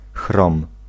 Ääntäminen
Ääntäminen France: IPA: [lə kʁom] Tuntematon aksentti: IPA: /kʁom/ Haettu sana löytyi näillä lähdekielillä: ranska Käännös Ääninäyte Substantiivit 1. chrom {m} Suku: m .